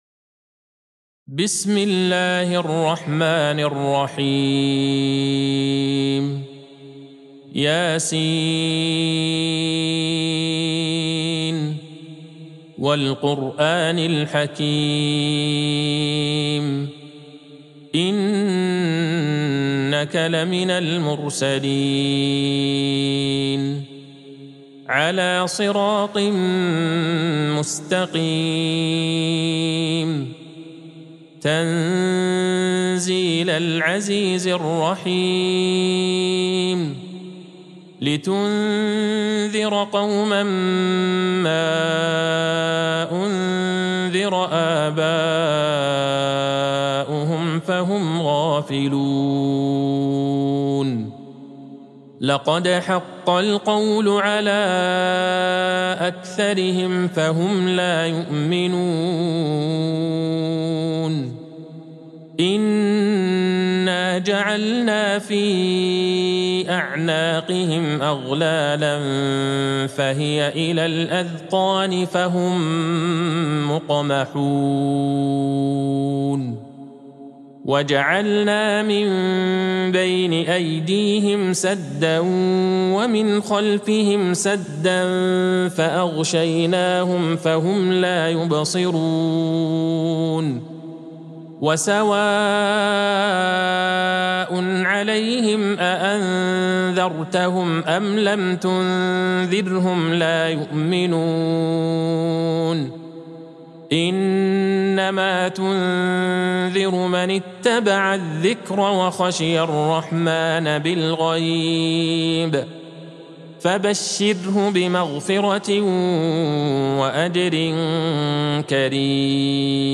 سورة يس Surat Ya Sin | مصحف المقارئ القرآنية > الختمة المرتلة ( مصحف المقارئ القرآنية) للشيخ عبدالله البعيجان > المصحف - تلاوات الحرمين